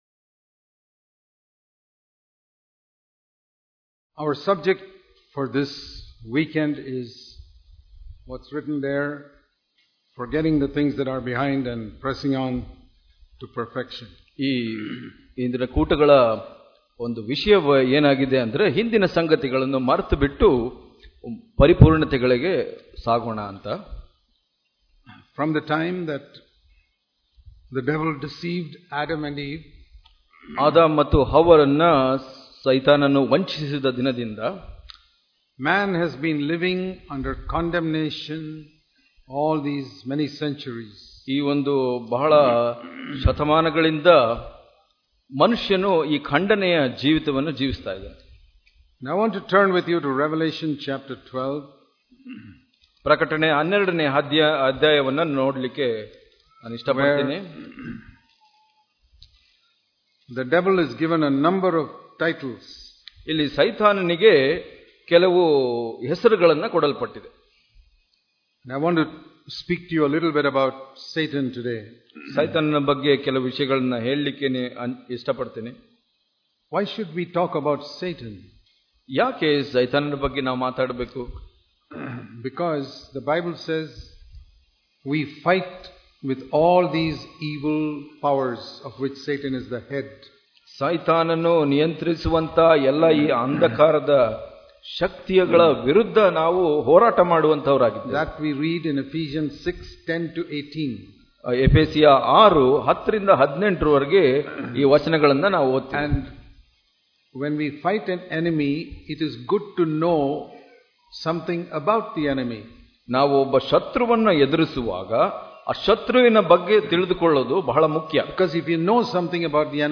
Hubli Conference 2018